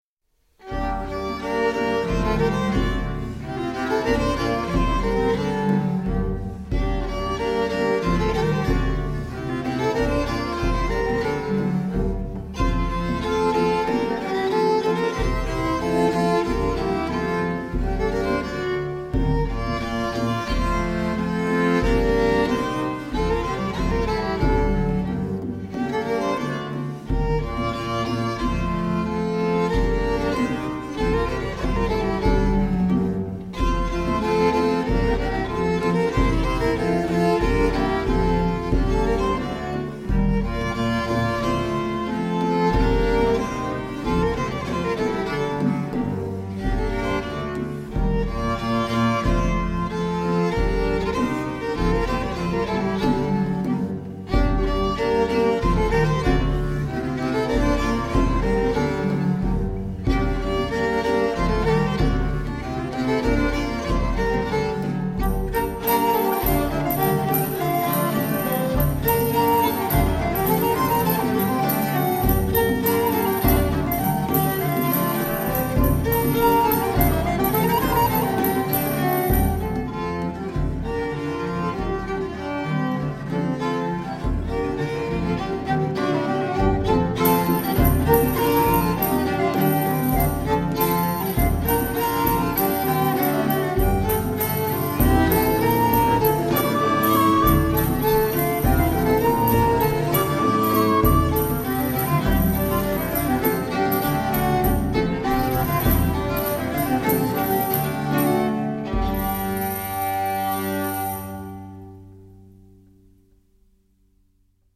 Branle
Chamber
A 16th-century French dance style which moves mainly from side to side, and is performed by couples in either a line or a circle.